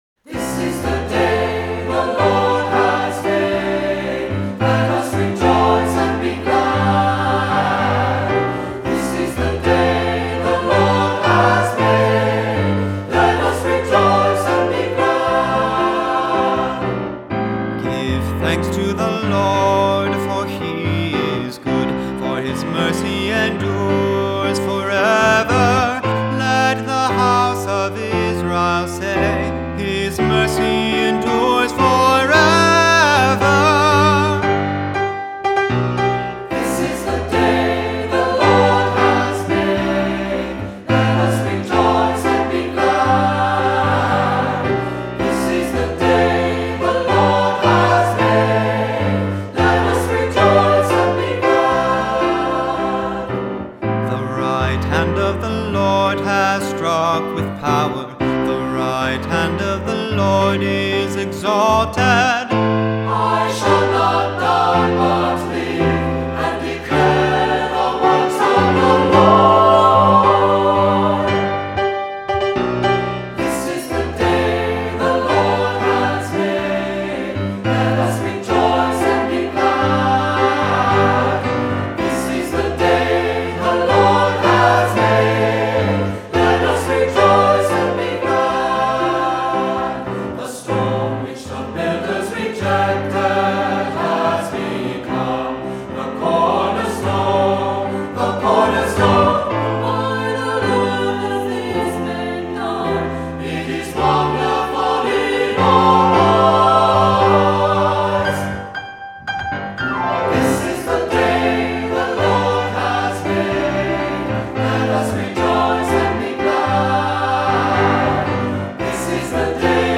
Voicing: SATB; Descant; Cantor; Assembly